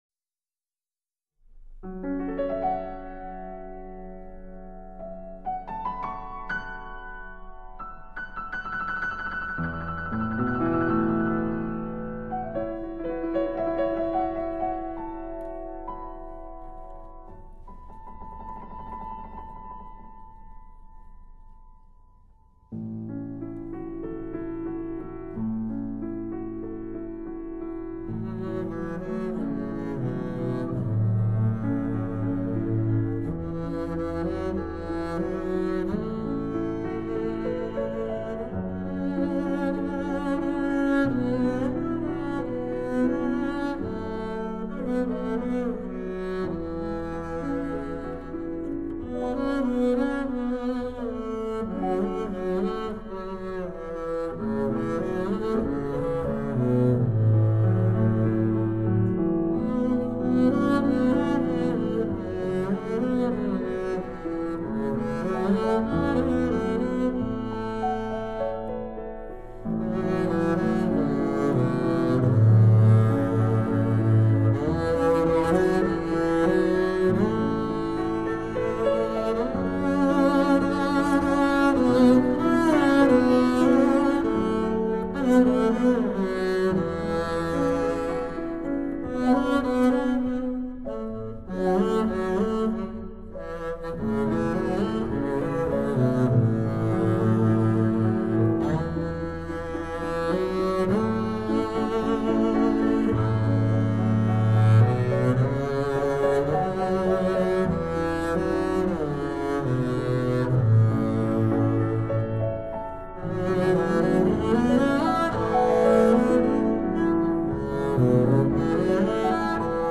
细节丰富　动态十足
低音提琴